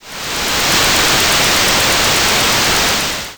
water.wav